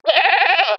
ヤギの鳴き声「メ〜」羊の鳴き声も「メ〜」です。
ヤギの鳴き声 着信音